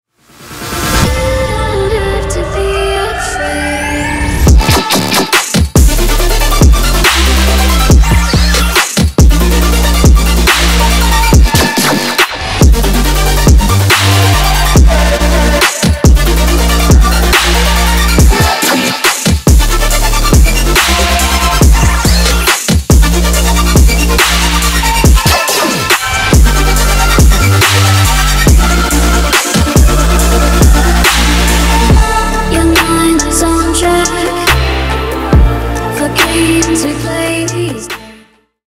Ремикс # Электроника